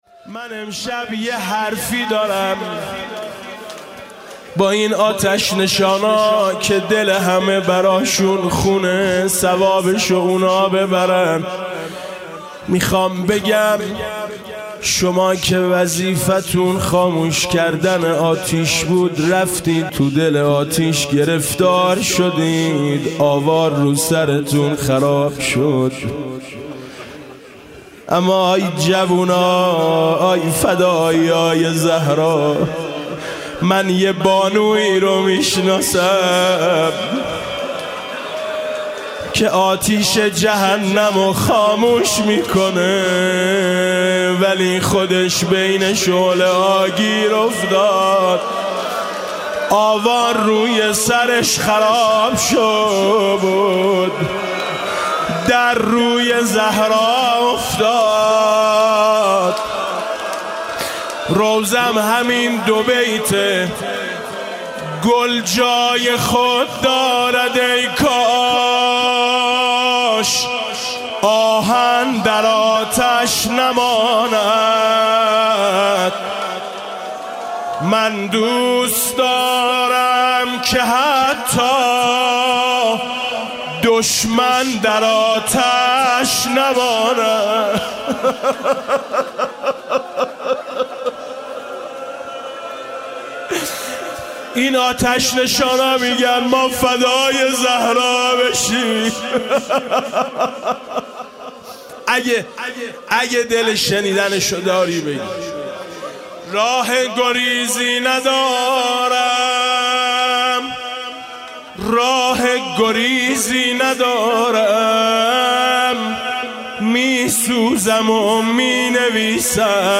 روضه شهادت آتش نشانان عزیز